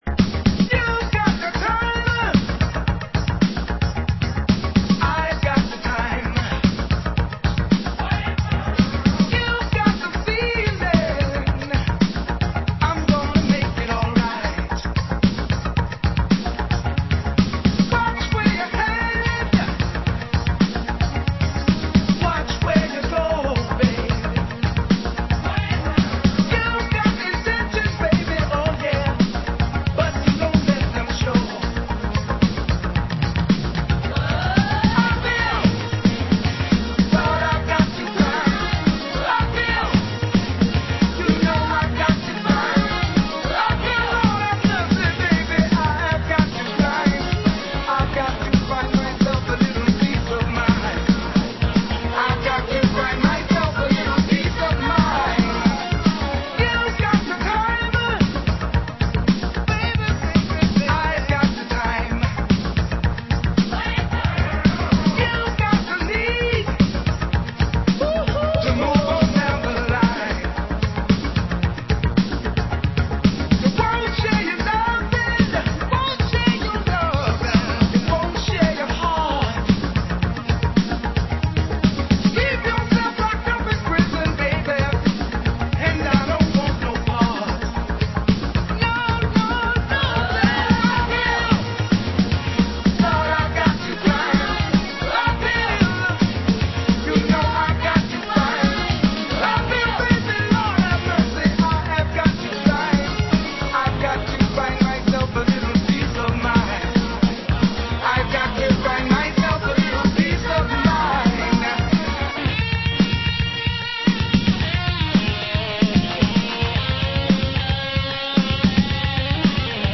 Genre: Old Skool Electro
Dub Mix